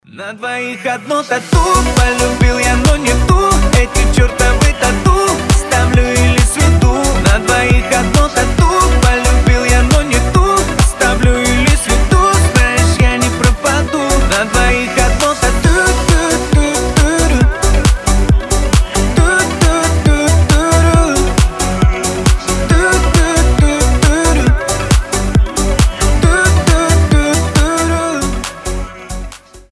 мужской голос
заводные
динамичные